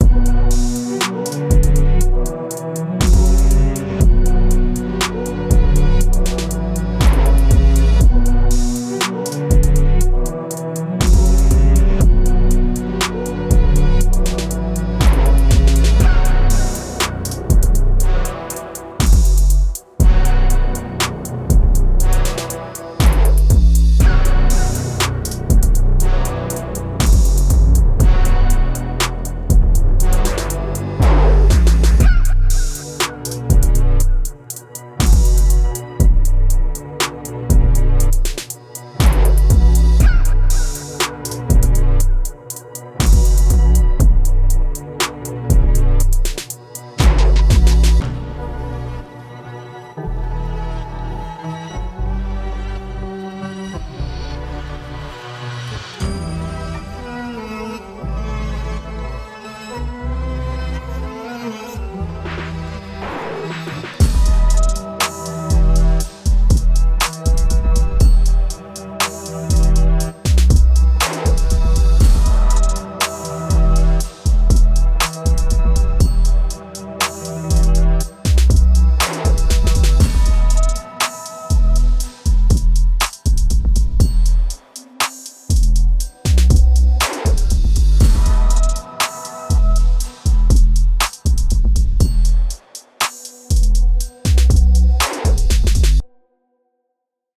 is your one-way ticket to luscious Trap and Hip Hop vibes.
an electrifying collection, filled with loops and samples
Trap sounds, drum loops, and samples